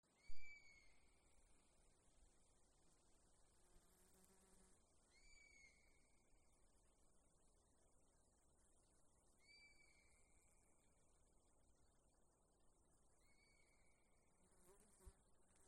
Eurasian Sparrowhawk, Accipiter nisus
Notes/pārlido ar saucieniem